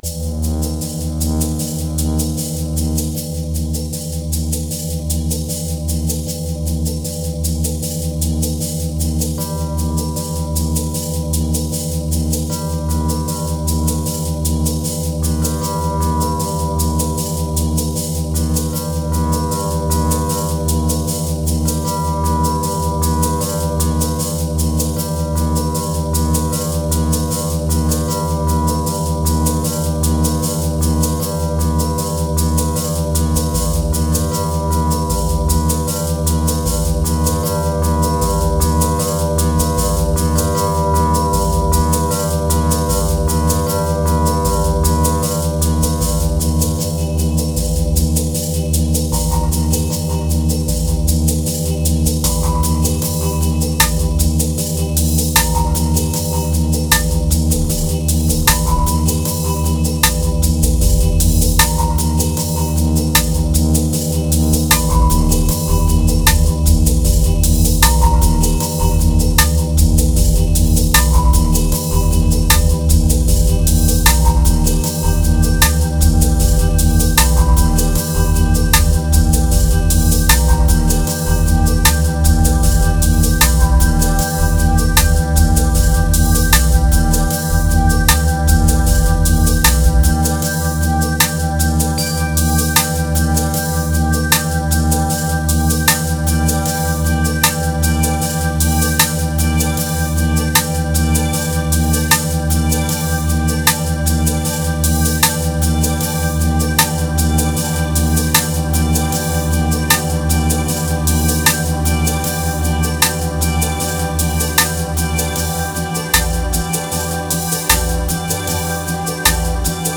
812📈 - 40%🤔 - 77BPM🔊 - 2020-11-19📅 - 448🌟
Memories Relief Moods Rim Rddim Signal Ladder Epic